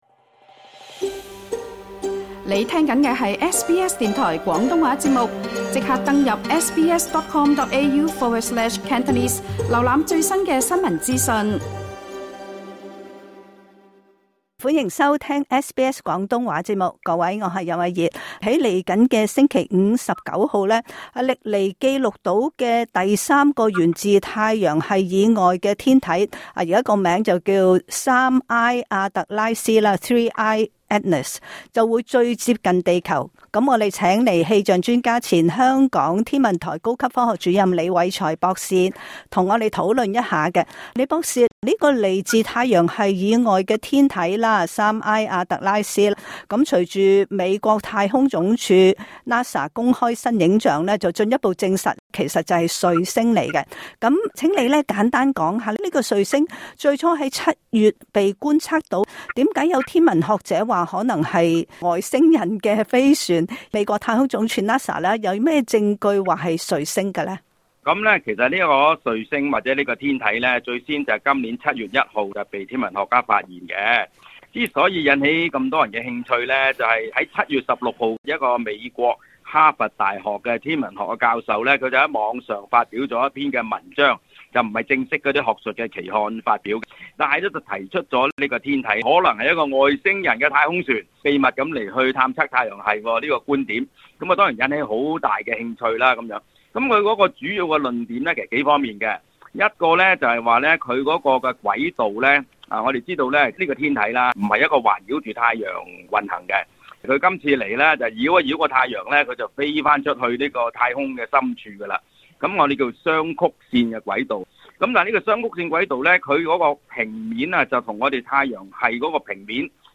詳情請聽今集訪問。